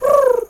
pigeon_2_call_calm_02.wav